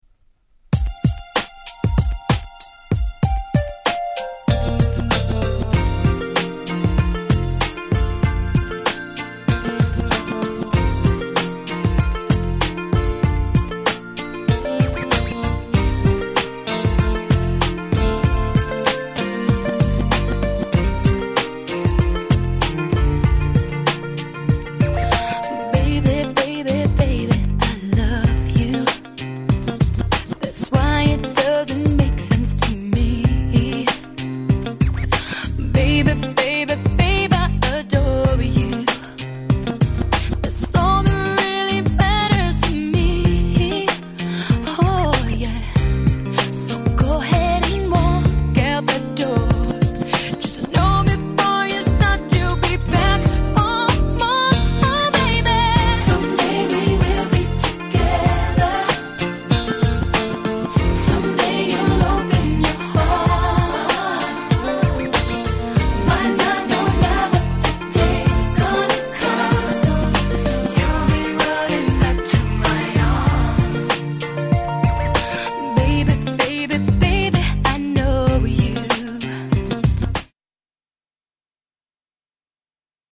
キャッチー系